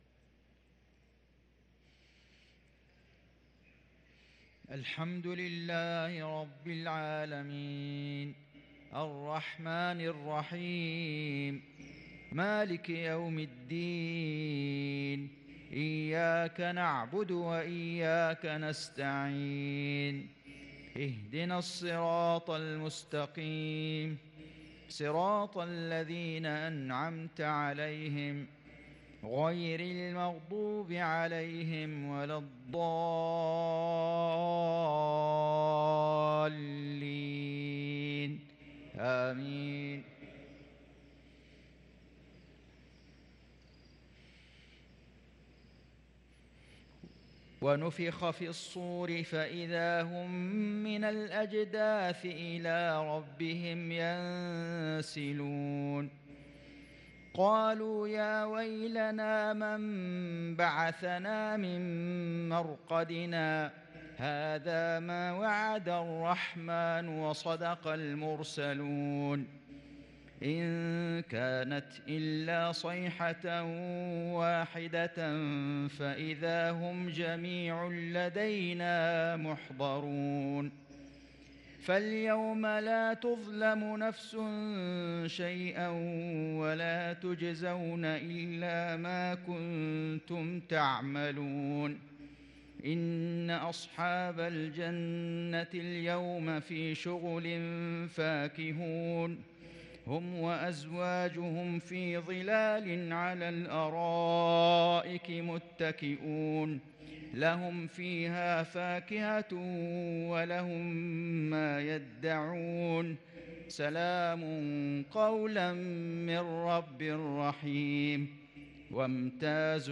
صلاة المغرب ١ شوال ١٤٤٣هـ من سورتي يس و القمر| Maghrib prayer from Surah Ya Sin & al-Qamar 2-5-2022 > 1443 🕋 > الفروض - تلاوات الحرمين